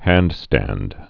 (hăndstănd)